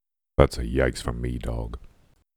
Tags: Gen Z Deep Voice Yikes